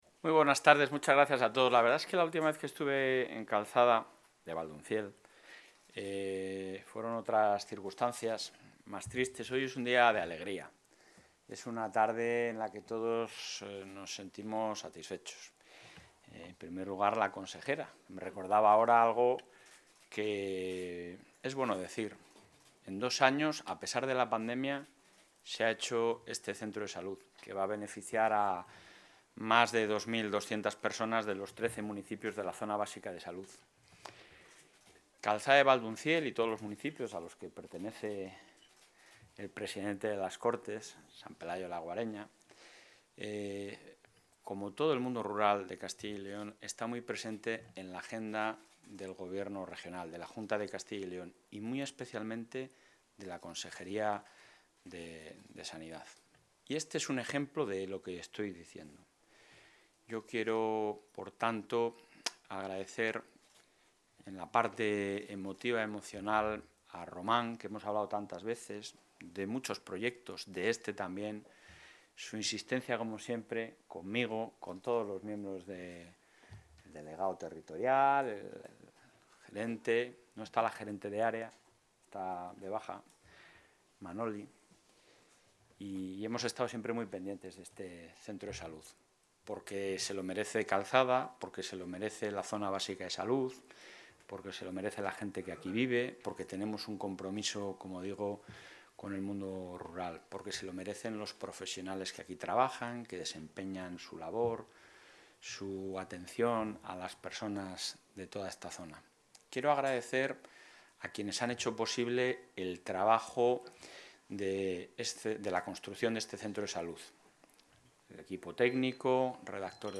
Intervención del presidente de la Junta.
El presidente de la Junta de Castilla y León, Alfonso Fernández Mañueco, ha inaugurado hoy el nuevo centro de Calzada de Valdunciel, en Salamanca, que atenderá a más de 2.200 pacientes de los 13 municipios de esta zona de salud gracias a un edificio más accesible, con mejores instalaciones y equipamiento que el anterior.